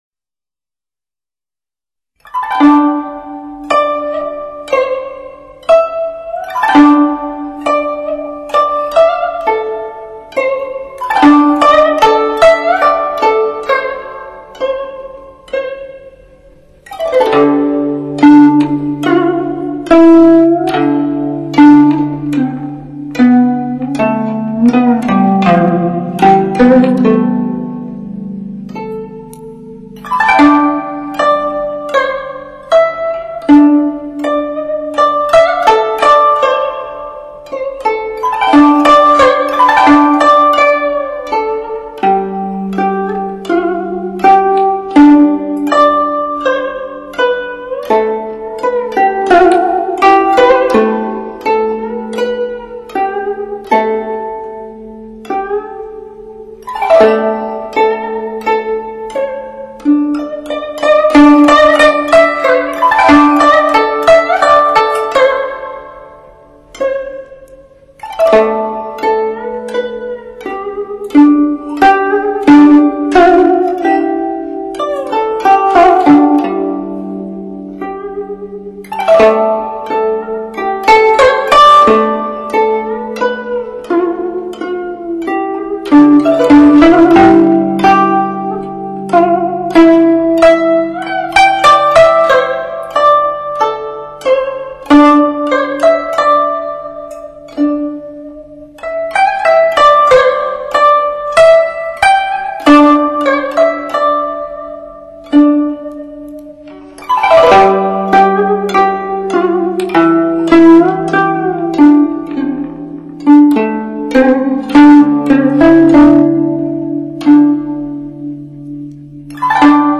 古筝
客家筝曲Track 4 《寒鸦戏水》 发挥了古筝清越透明的音色，余音悠长， 音韵委婉，乐曲以轻盈的旋律，清新的格调，别致的韵味，表达了寒鸦在水中 嬉戏的情趣。